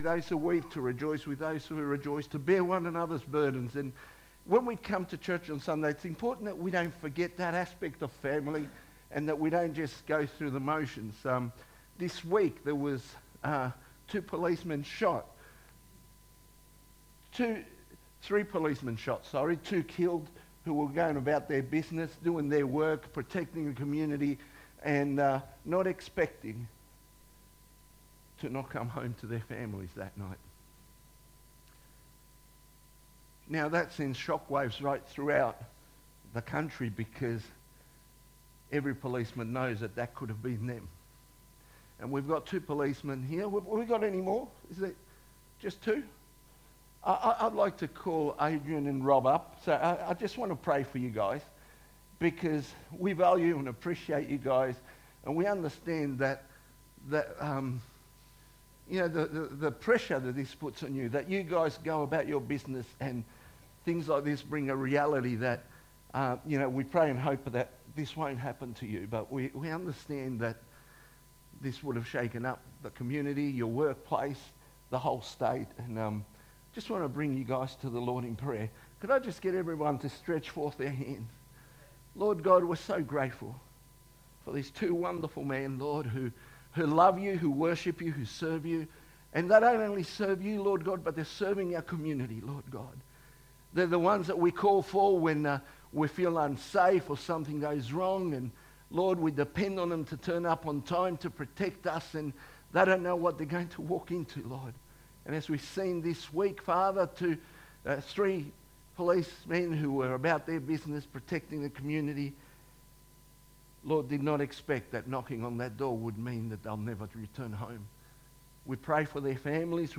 2025 • 30.80 MB Listen to Sermon Download this Sermon Download this Sermon To download this sermon